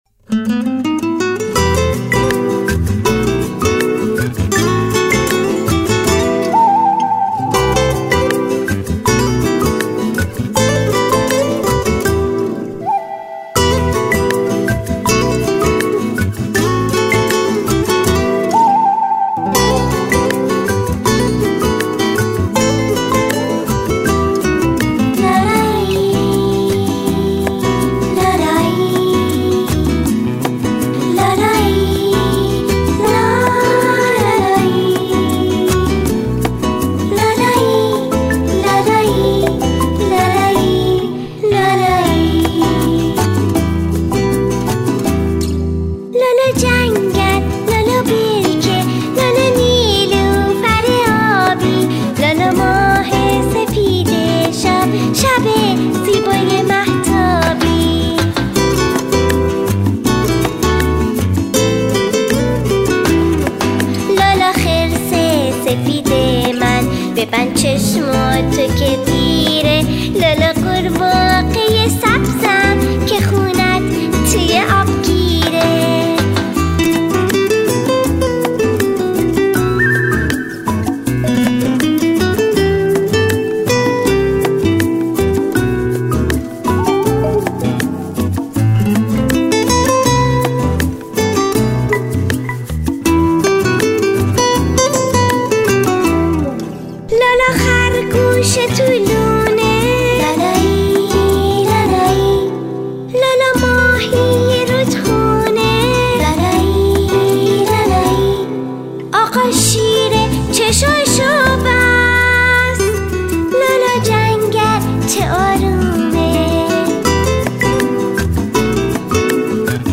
لالایی لالا گل نیلوفر